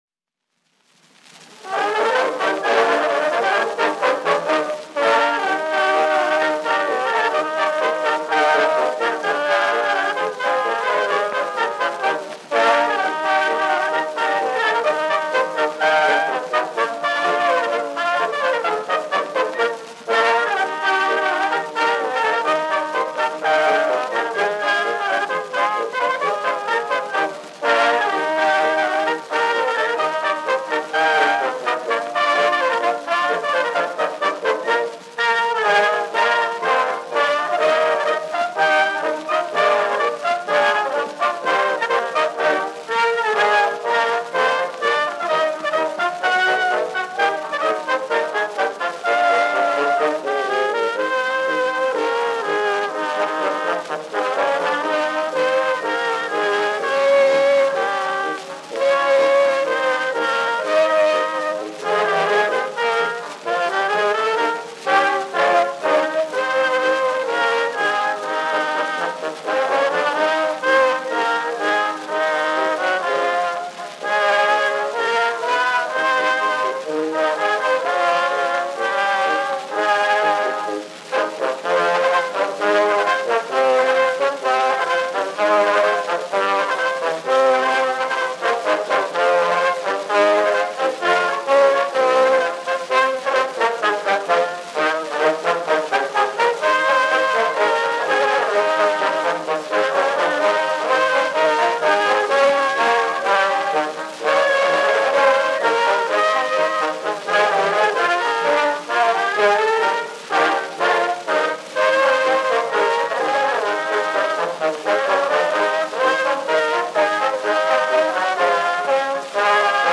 1910 Indestructable #3080  John Lacalle's Band